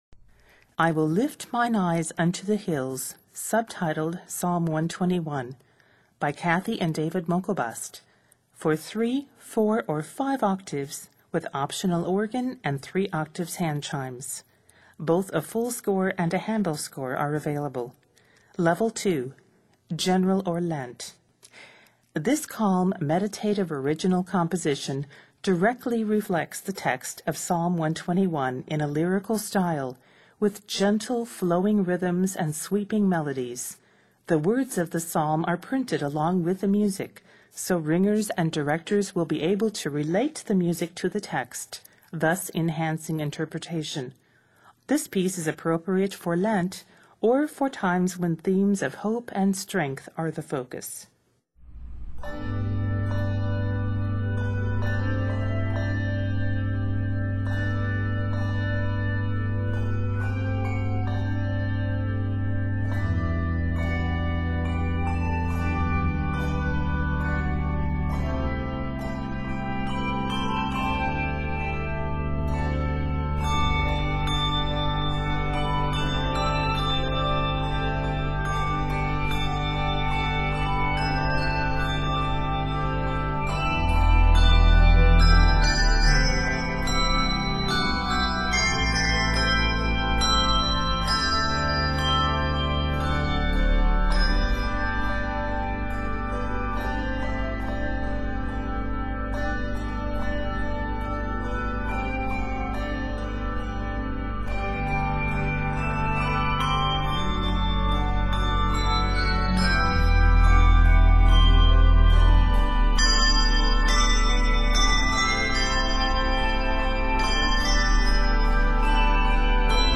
Written in G Major and C Major, measures total 106.